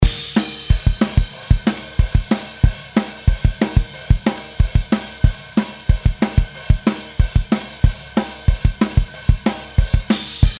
あと一つ、キックはイント/アウトx2の3本体制で録りましたがインの音をもと重心低くしたいなぁ、と。
いい感じで重心が下がって迫力も出ていますね。